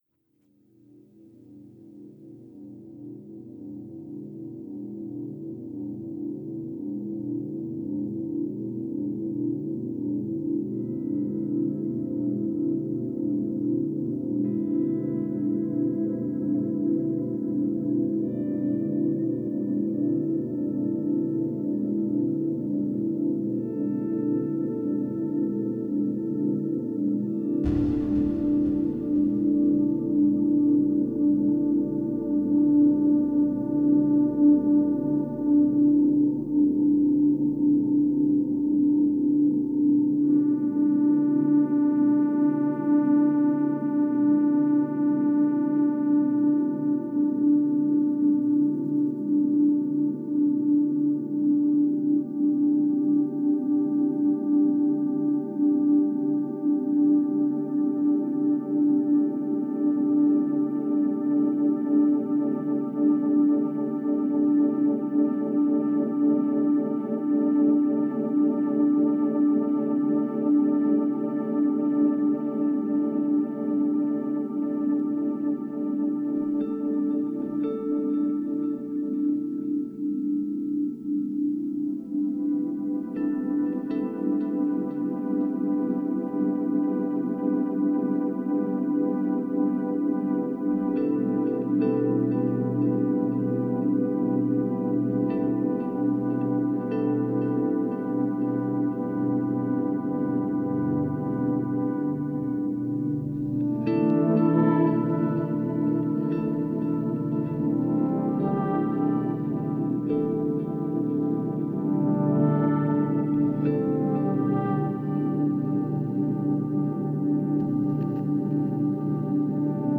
Genre: Ambient, New Age, Meditative.